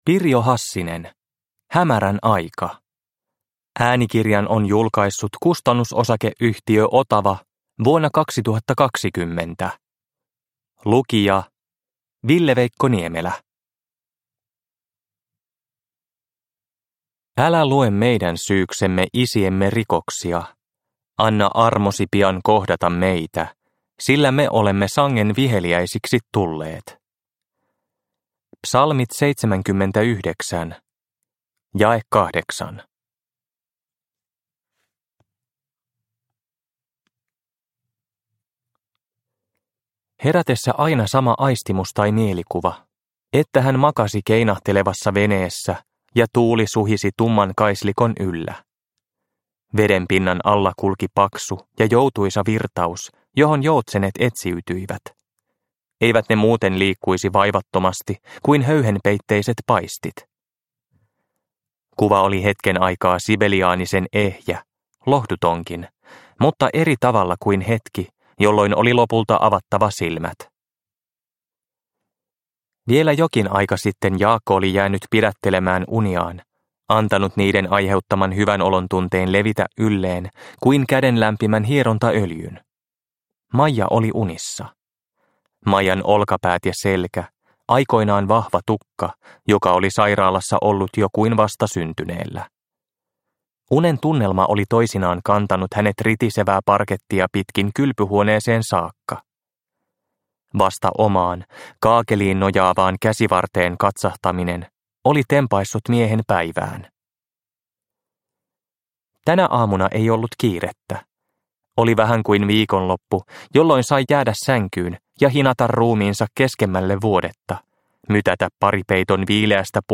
Hämärän aika – Ljudbok – Laddas ner